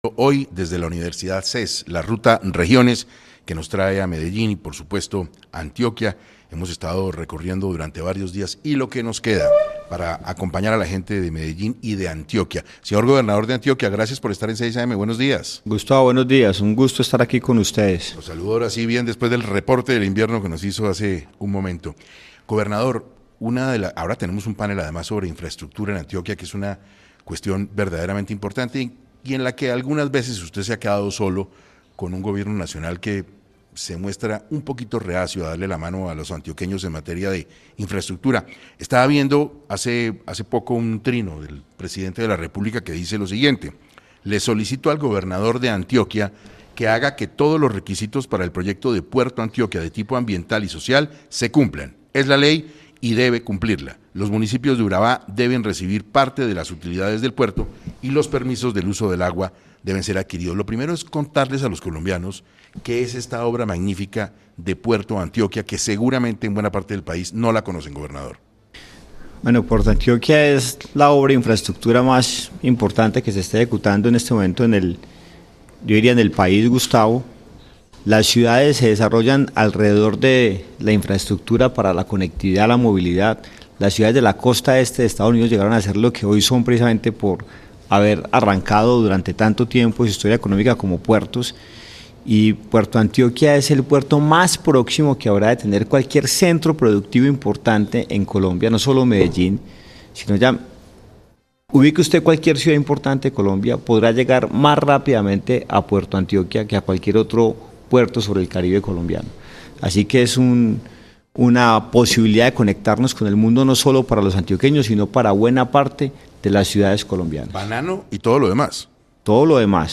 Andrés Julián Rendón, gobernador de Antioquia, habló en 6AM sobre los proyectos de esta región en infraestructura vial, educación y seguridad.